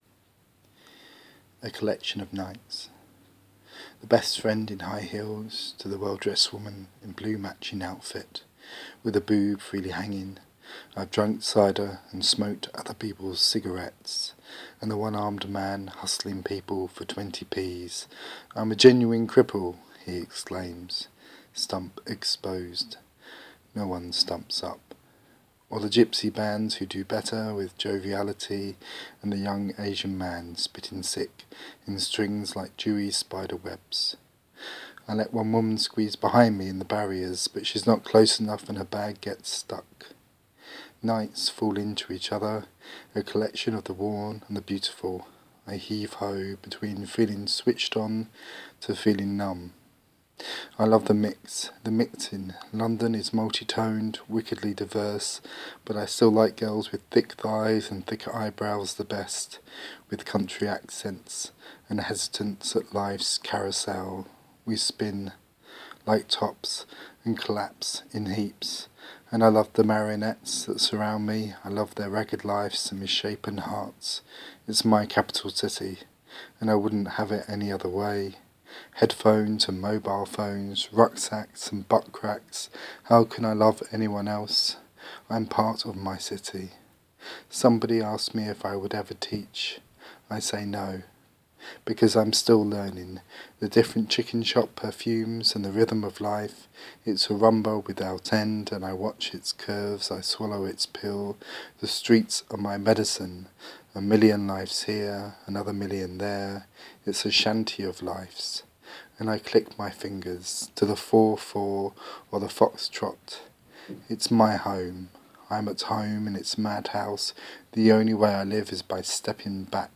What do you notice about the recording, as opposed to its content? A collage of my london in the dark